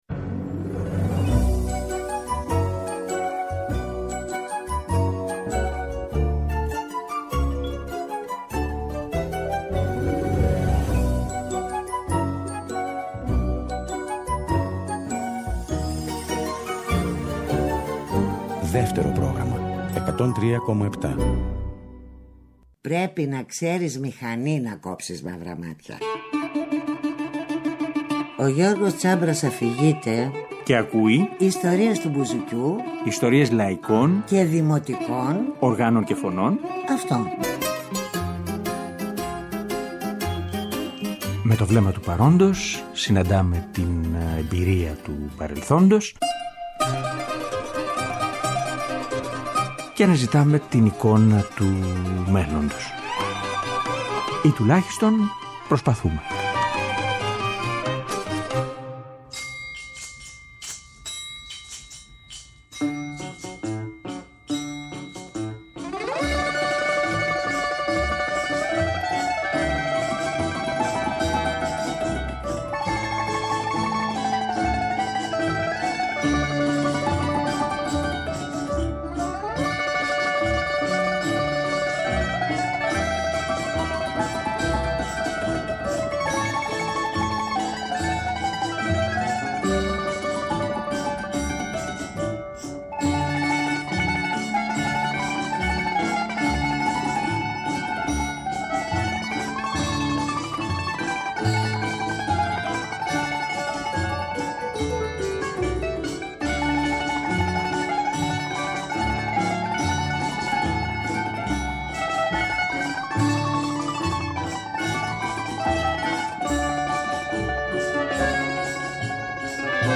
Μ’ αυτό ως αφορμή, είπαμε να περιπλανηθούμε για λίγο στην δισκογραφία τους, με ούτι και τραγούδι, στα χρόνια του ’90 και του 2000.